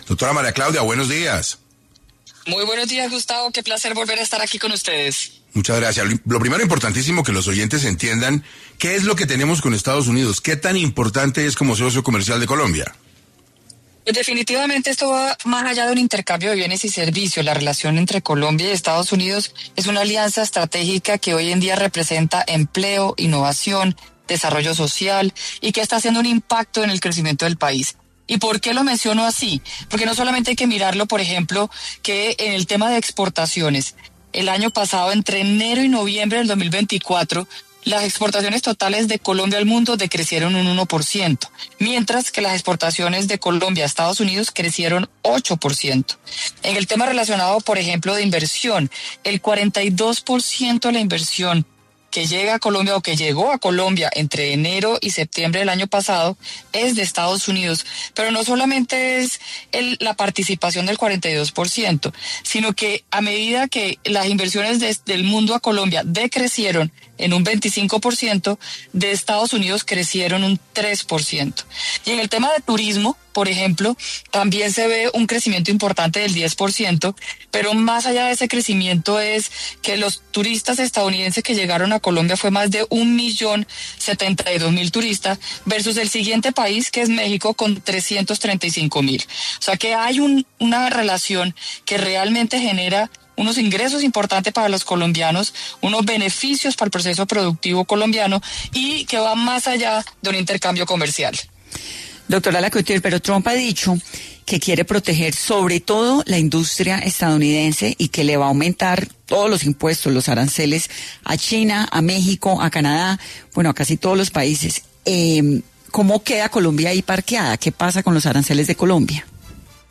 En 6AM de Caracol Radio estuvo María Claudia Lacouture, presidenta AmCham Colombia, para hablar sobre la segunda presidencia de Donald Trump y qué viene para la relación comercial entre Estados Unidos y Colombia.